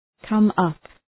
Προφορά
{‘kʌm,ʌp}